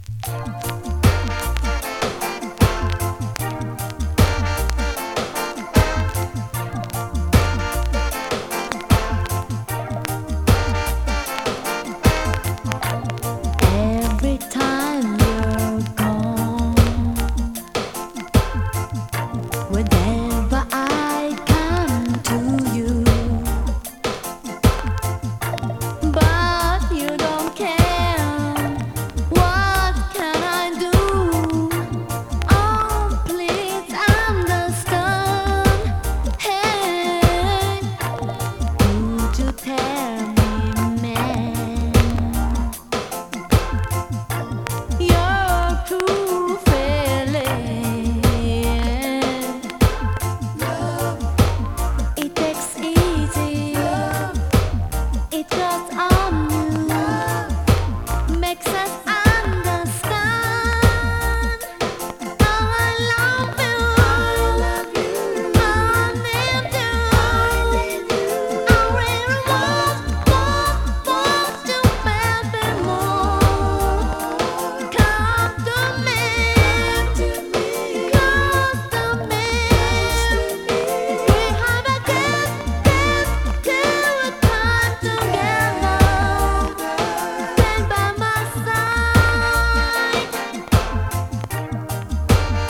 コメントレアジャパレゲ!!
スリキズ、ノイズそこそこありますが